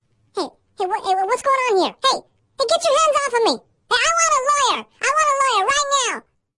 描述：一个人声线的记录和处理，就像一个卡通花栗鼠说的一样。 录音是在Zoom H4n上进行的。使用MOTU Digital Performer中的Spectral Effects进行操纵